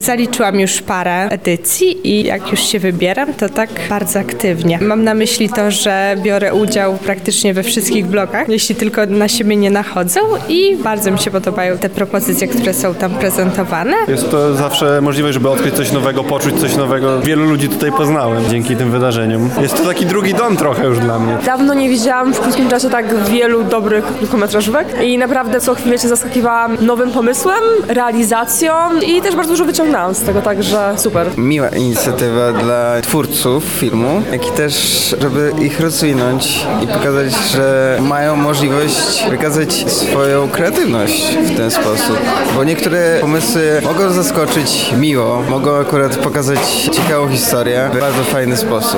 20 urodziny Złotych Mrówkojadów, relacja
Opinie-widzow_01-2.mp3